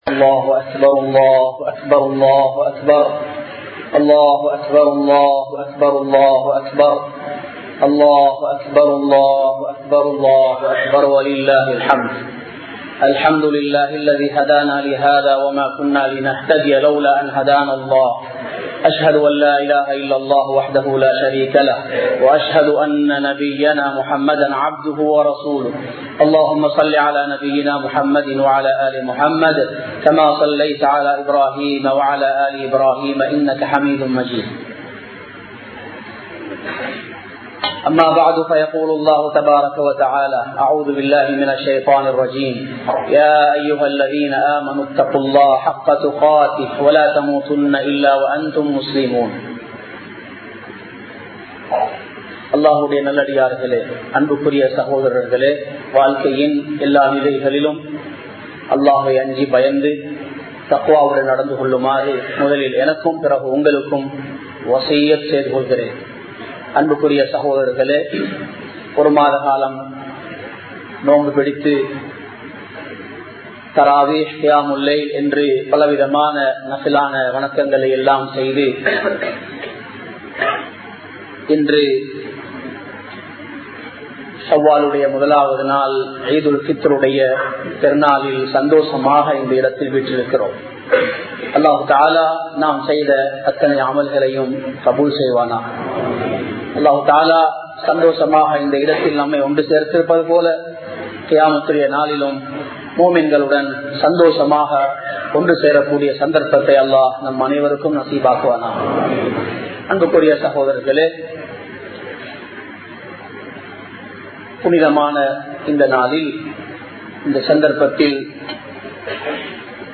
Eid Ul Fidhur | Audio Bayans | All Ceylon Muslim Youth Community | Addalaichenai